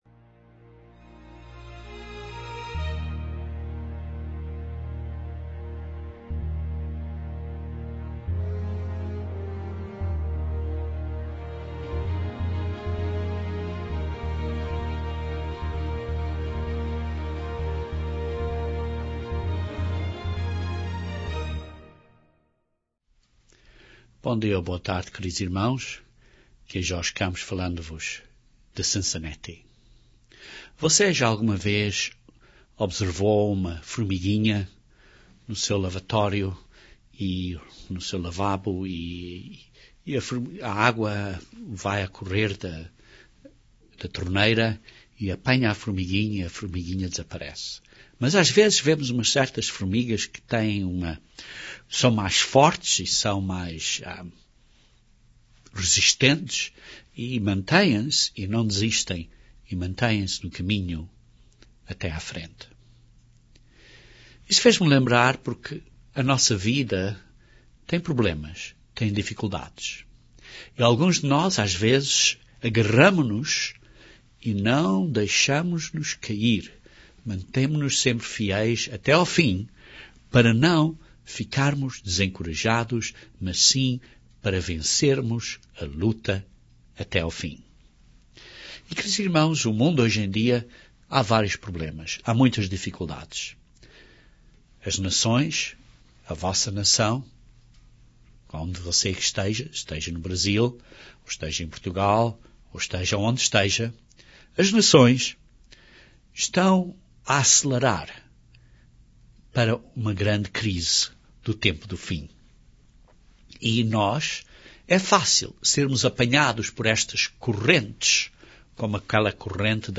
Precisamos de aprender como usar o poder espiritual de encorajamento que procede de Deus. Este sermão descreve três âreas que nos podem ajudar a vencer o desencorajamento.